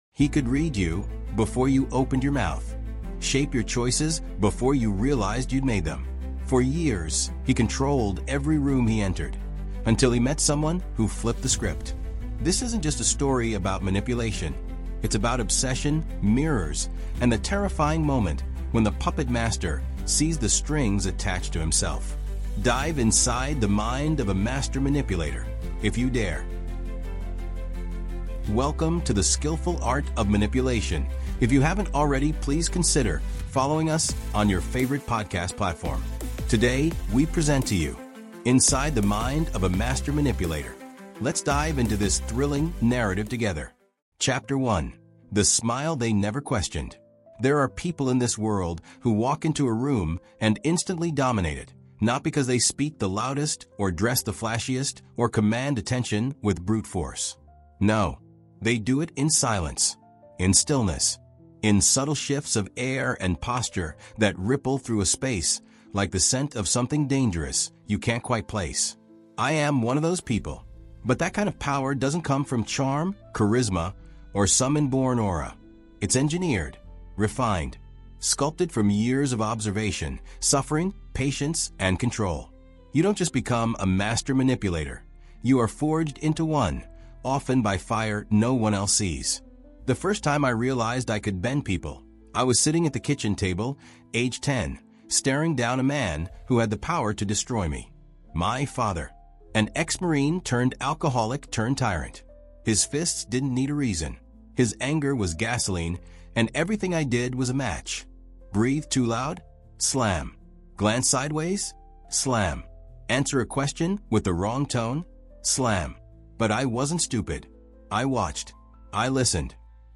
Inside The Mind Of A Master Manipulator | Audiobook